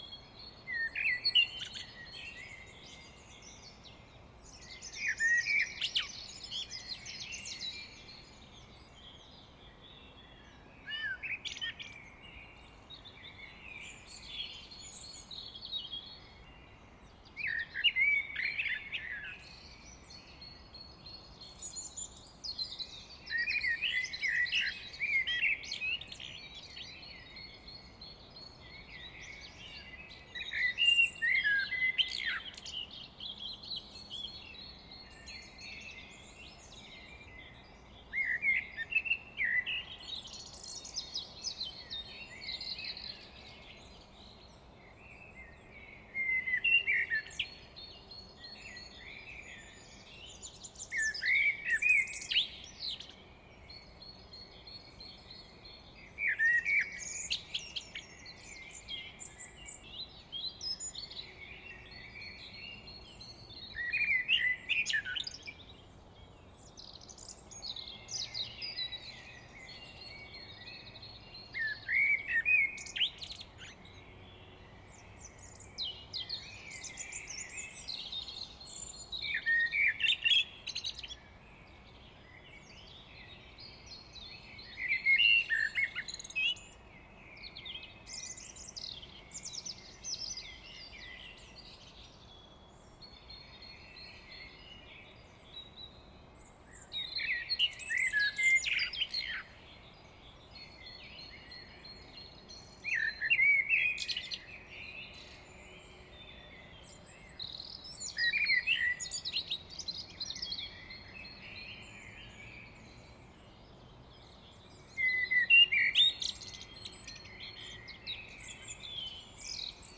DE: Einen wunderschönen guten Morgen und ein schönes Wochenende wünscht euch diese Amsel, deren Gesang ich am 18. März 2023 aufgenommen habe.
EN: This blackbird I recorded on March 18th wishes you a wonderful good morning and a nice weekend.